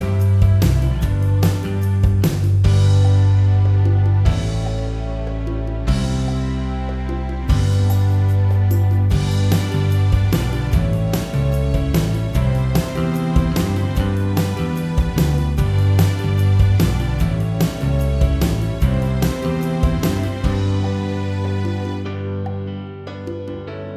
Minus All Guitars Pop (1990s) 3:33 Buy £1.50